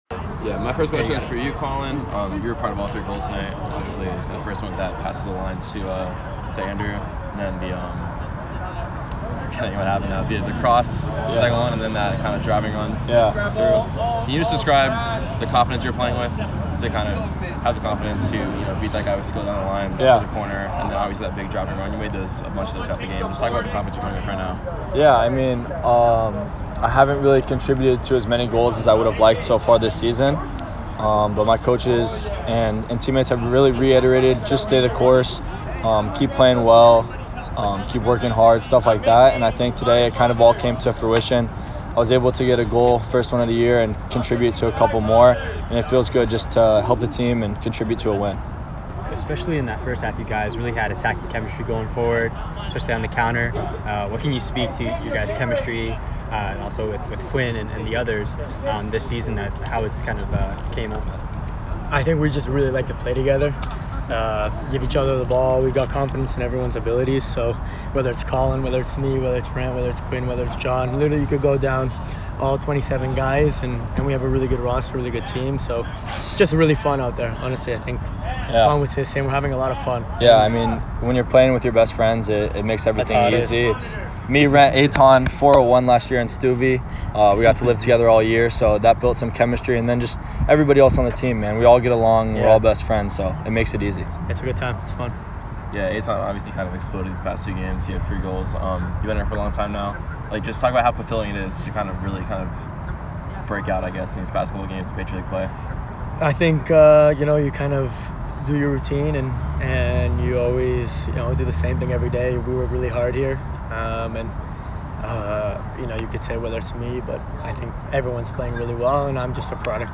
Men's Soccer / Holy Cross Postgame Interview